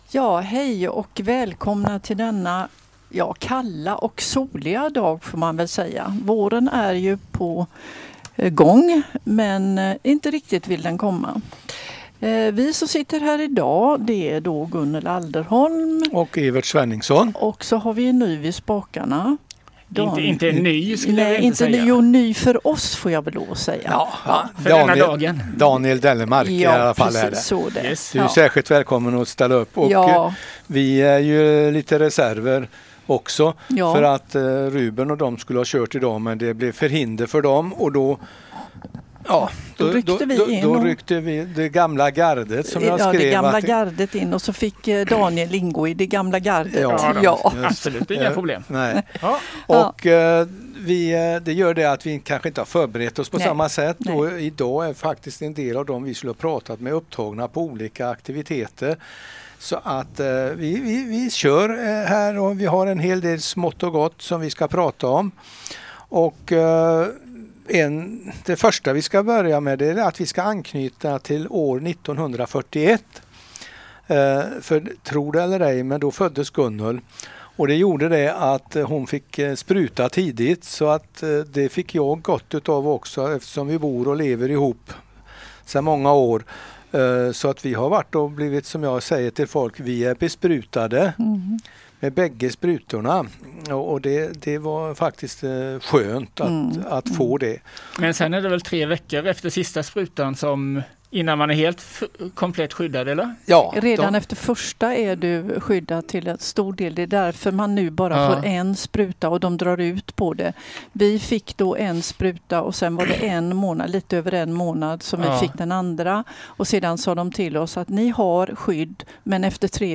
Musiken är bortklippt av upphovsrättsliga skäl.